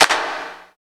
108 W.CLAP-R.wav